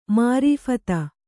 ♪ mārīphata